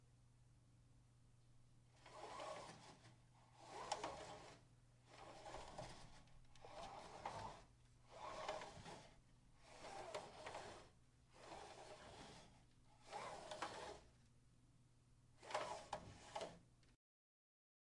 150018声音设计SFX项目 " 移动百叶窗OWI
描述：木制百叶窗来回噼啪作响
Tag: 滑动 百叶窗 clackig -back - 向前